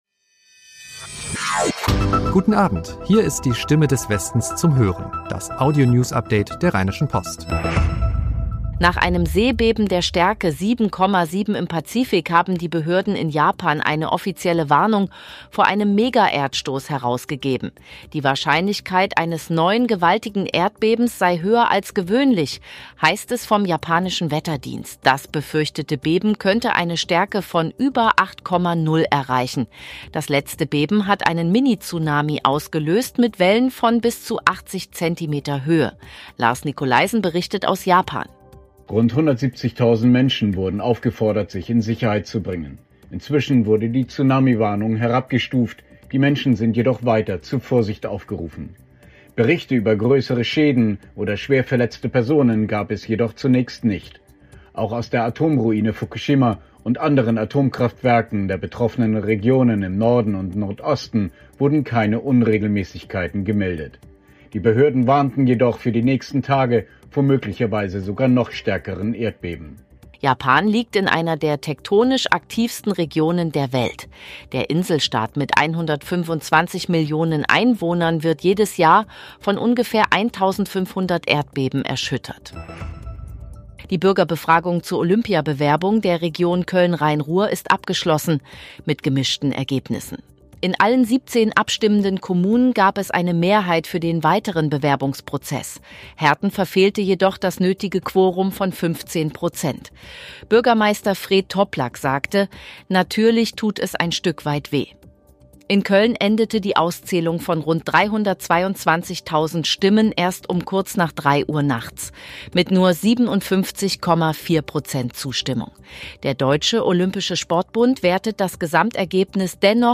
Die Nachrichten von Montag, 20.04.2026 zum Hören